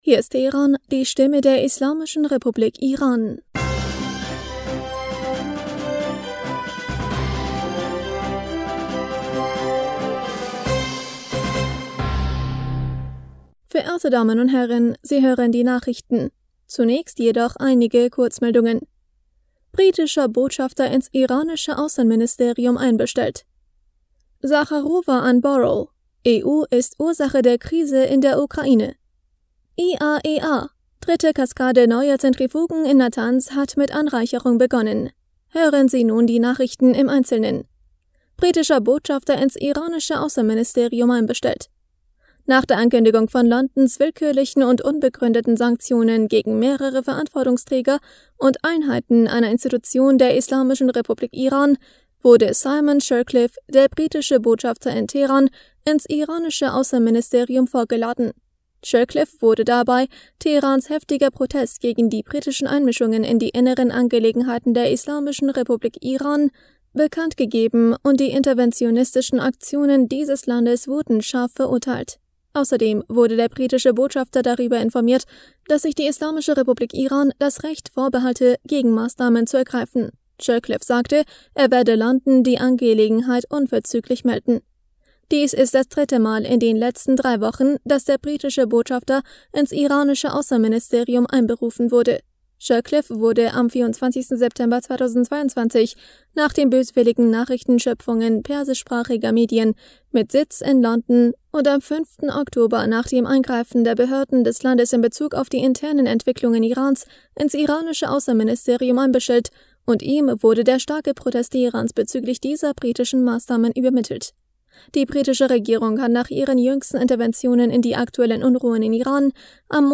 Nachrichten vom 11. Oktober 2022